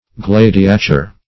Search Result for " gladiature" : The Collaborative International Dictionary of English v.0.48: Gladiature \Glad"i*a*ture\, n. [L. gladiatura.]